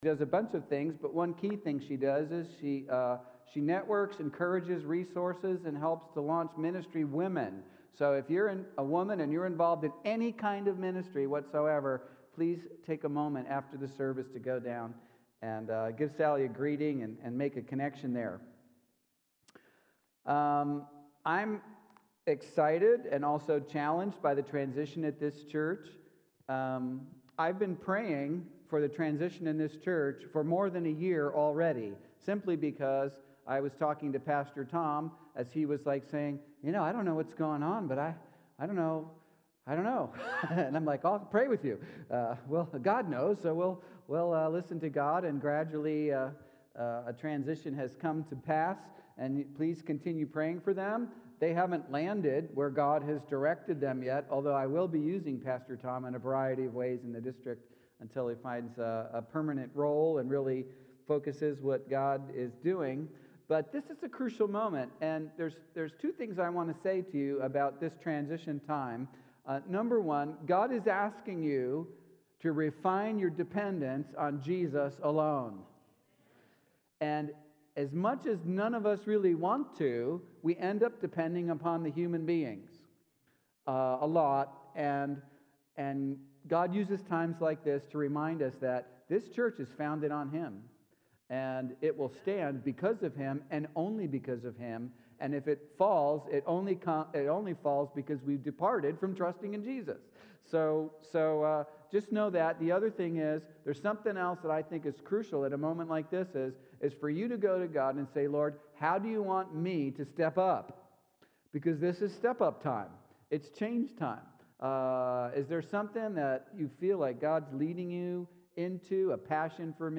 Sermon Archives | Syracuse Alliance Church